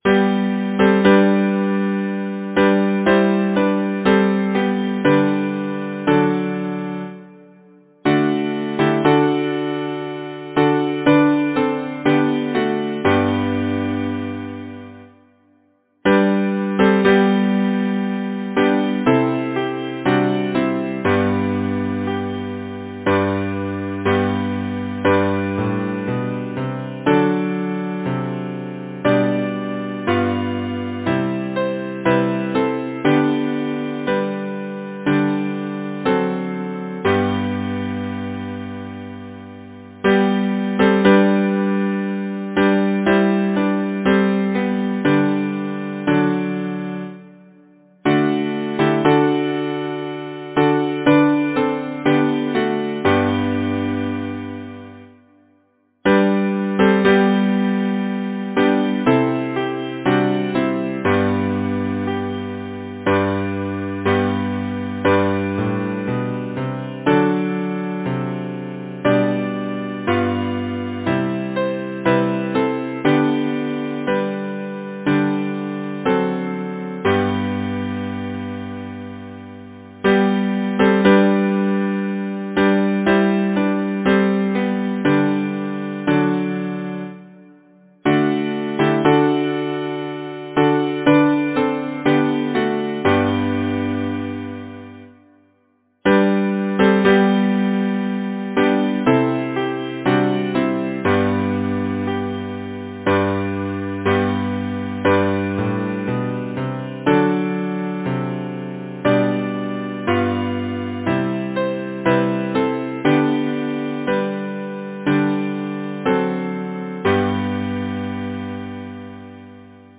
Title: The Silent Grove Composer: Alfred Beirly Lyricist: Number of voices: 4vv Voicing: SATB Genre: Secular, Partsong
Language: English Instruments: A cappella